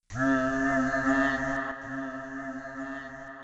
vox kanye would use me.wav